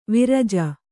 ♪ viraja